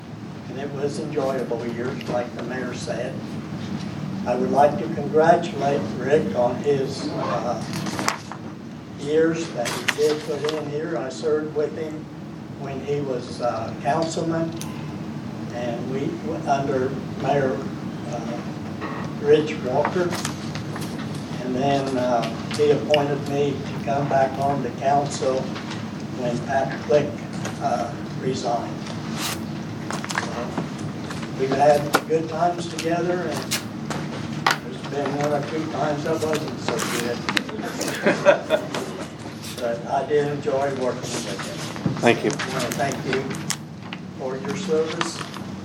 A former City of Vandalia Alderman addressed Mayor Gottman at what would be the Mayor’s final meeting and also talked about a potential new fundraiser for the National Road Interpretive Center.
Swarm spoke about Mayor Gottman on what would be the Mayor’s final City Council meeting this past Monday.